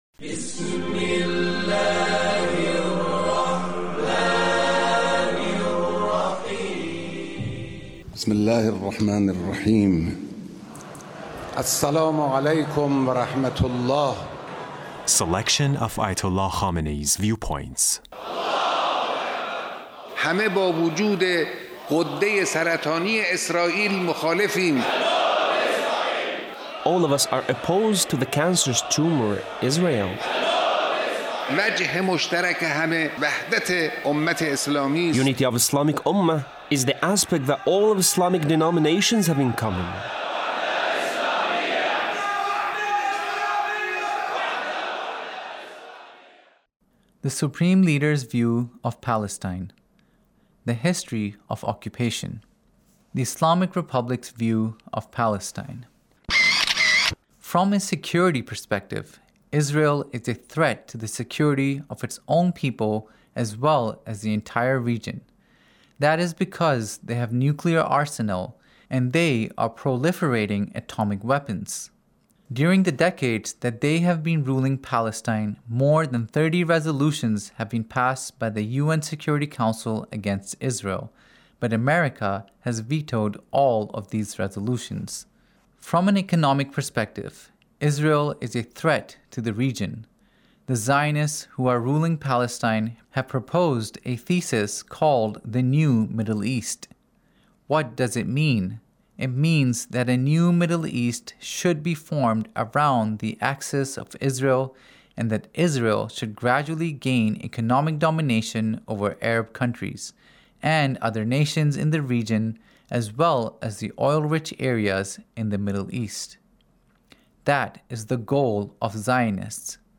Leader's Speech in a Meeting with the Three Branches of Government Repentance